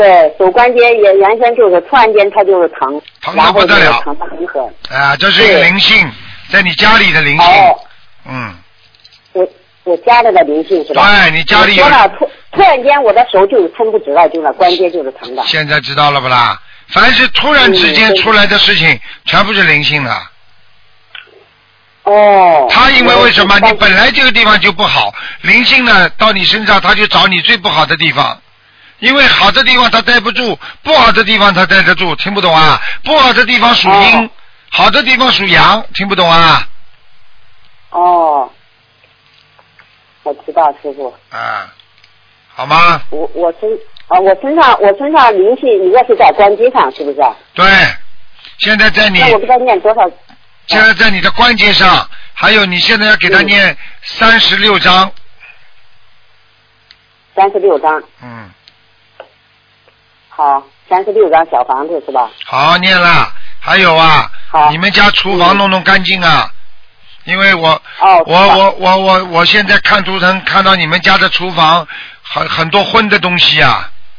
目录：2014年剪辑电台节目录音集锦